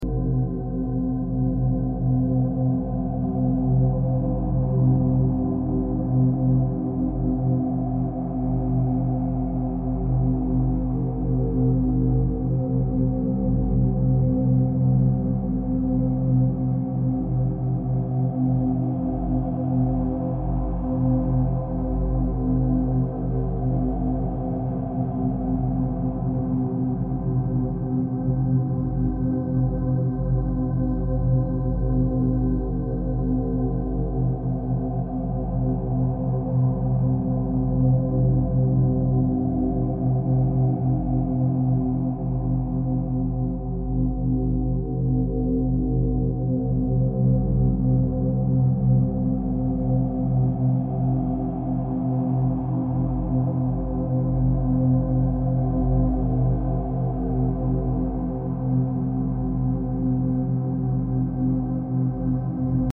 Immerse yourself in powerful delta wave frequencies with this deep healing soundscape featuring 174Hz and 741Hz binaural beats, enhanced by the timeless symbolism of the Flower of Life sacred geometry.
🔹 Delta Binaural Beats – Promote deep sleep, cellular repair, and subconscious reprogramming.
🎧 Use headphones for full binaural effect.